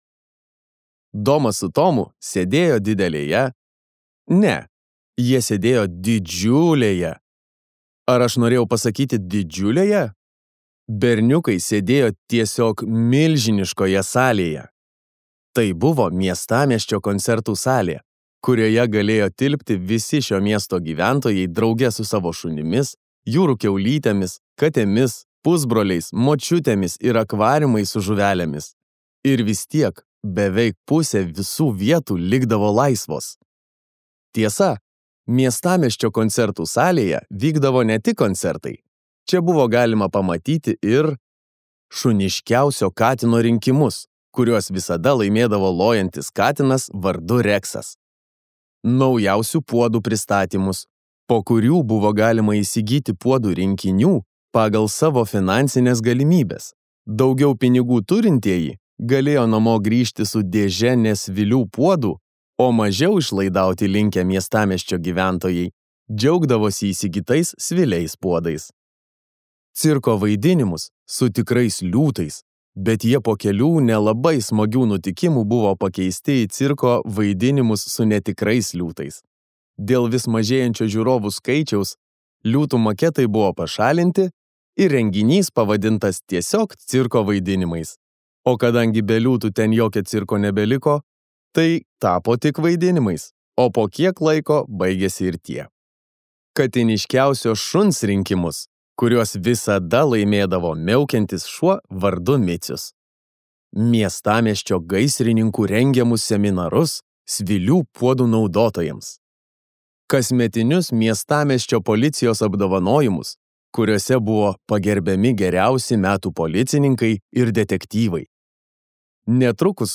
Skaityti ištrauką play 00:00 Share on Facebook Share on Twitter Share on Pinterest Audio Domas ir Tomas.